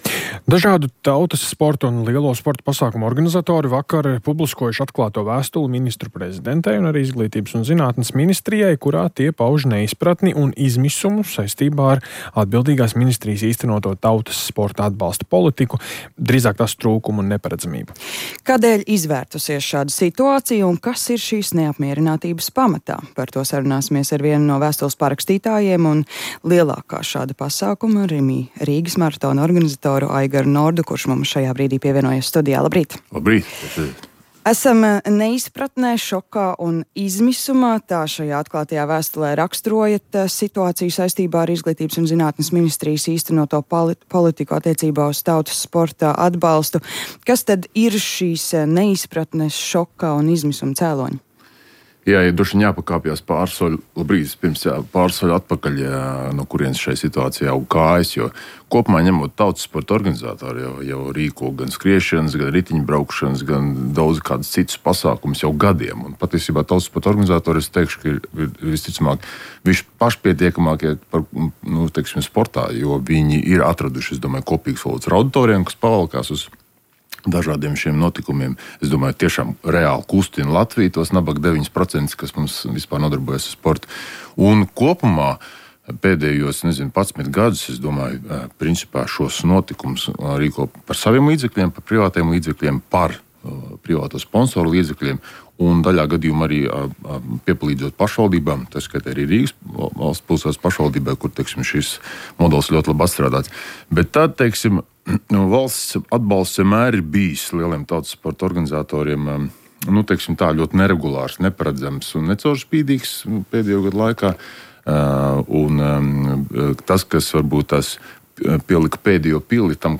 Revidenti: Valsts nav sekojusi līdzi Covid laikā veikto ieguldījumu "airBaltic" atgūšanai 29 touko · Rīta intervija Kuuntele myöhemmin Kuuntele myöhemmin Merkitse kuunnelluksi Arvioi Ladata Mene podcastiin Jaa